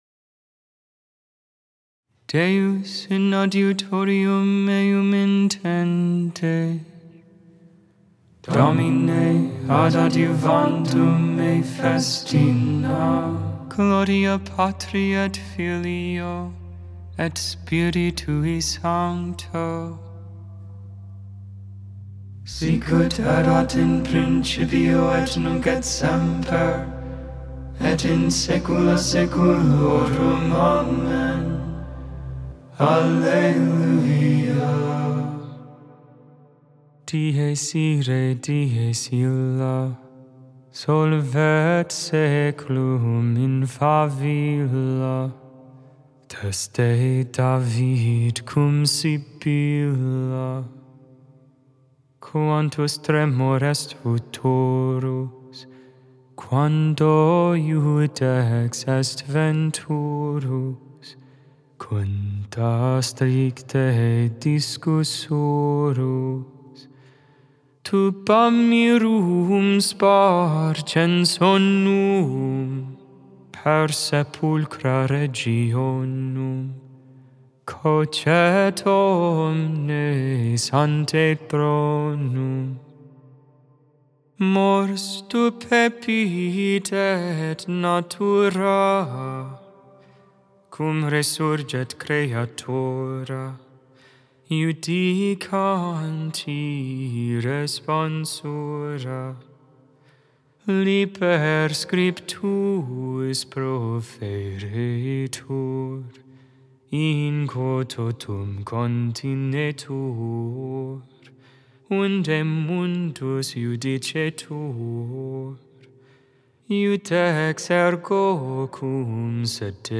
Join for morning (Lauds) and evening (Vespers) prayer. All Hymns, Psalms, and Prayer sung.